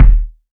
Kicks
KICK.87.NEPT.wav